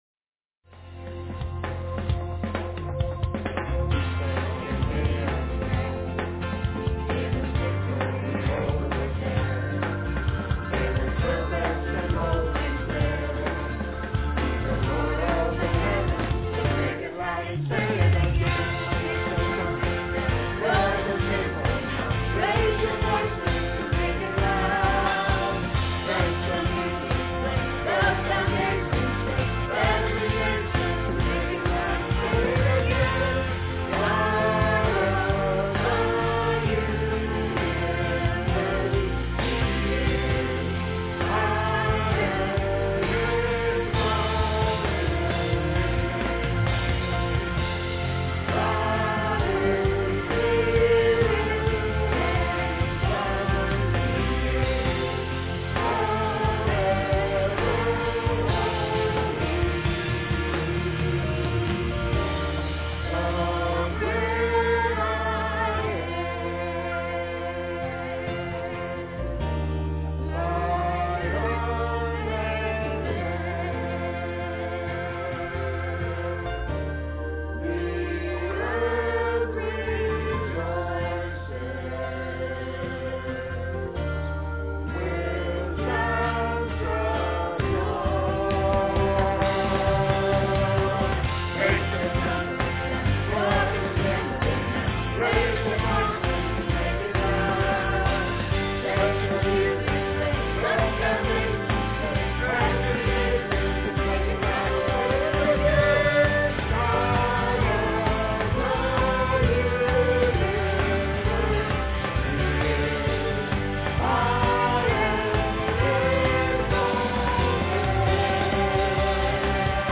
PLAY A Christian's Labor, Sep 3, 2006 Scripture: Colossians 1:28,29. Scripture Reading